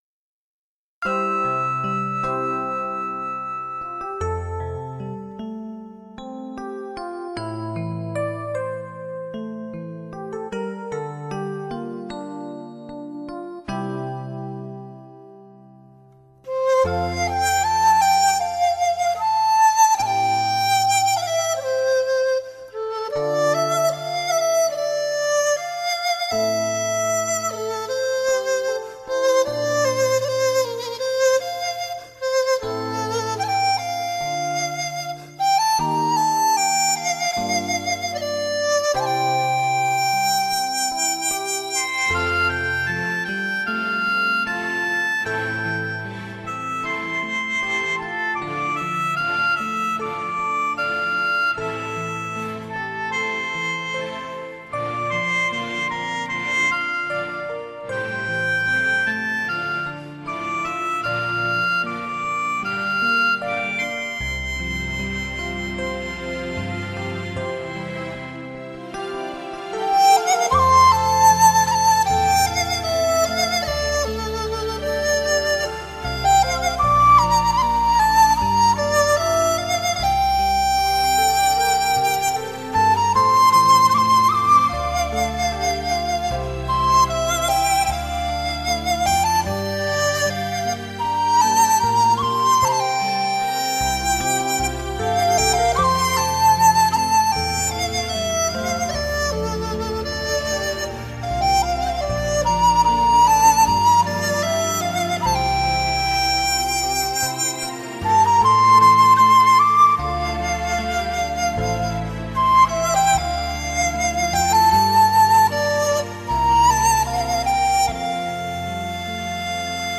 竹笛，雅号横吹，发音动人，婉转，
把您带入鸟语花香或高山流水的意境之中。